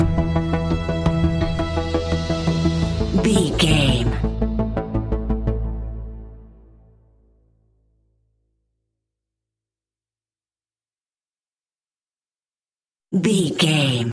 A great piece of royalty free music
In-crescendo
Thriller
Aeolian/Minor
ominous
haunting
eerie
strings
synthesiser
drums
horror music